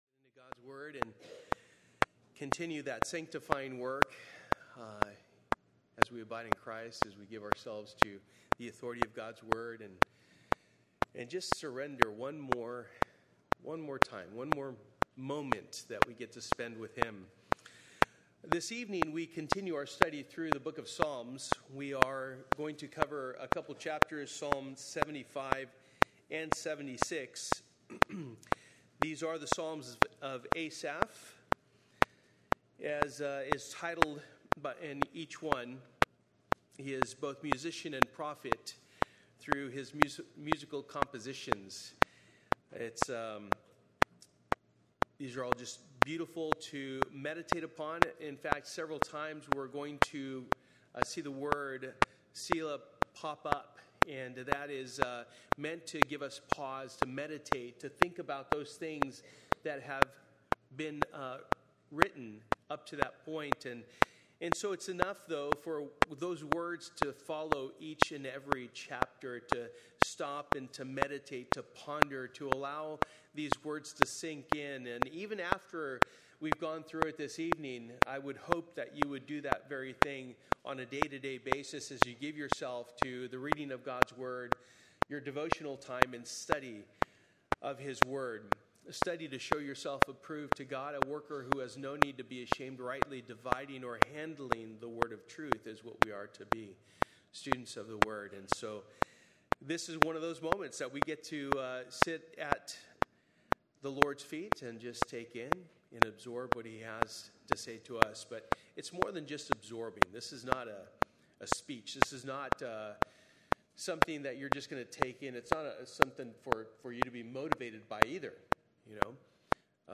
Psalm 75-76 Service: Wednesday Night « Submission and Understanding Day 1